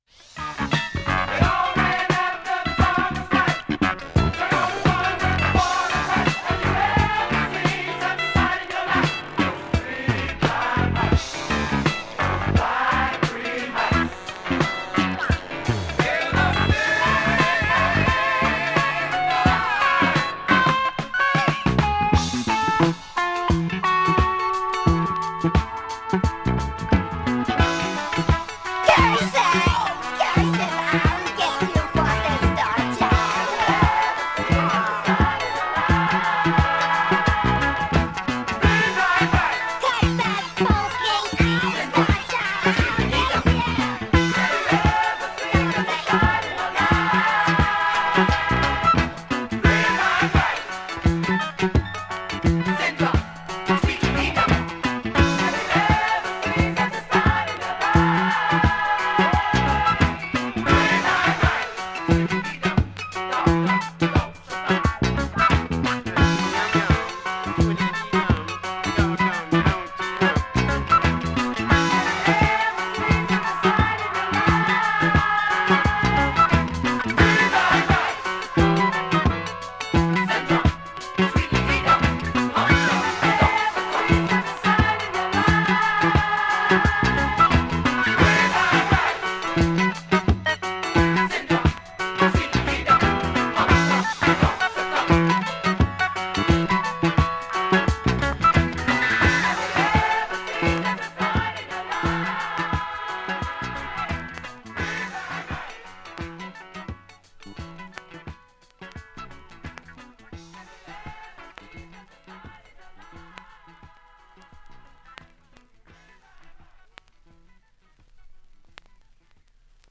音質良好全曲試聴済み。
３分の間に周回プツ出ますがかすかで、
ほか５回までのかすかなプツが１箇所
３回までのかすかなプツが３箇所
単発のかすかなプツが３箇所